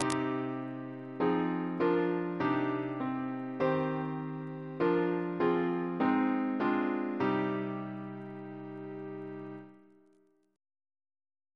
CCP: Chant sampler
Single chant in C minor Composer: C. Hylton Stewart (1884-1932), Organist of Rochester and Chester Cathedrals, and St. George's, Windsor Reference psalters: ACB: 82; CWP: 2; RSCM: 209